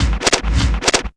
LOOP SCRATCH.wav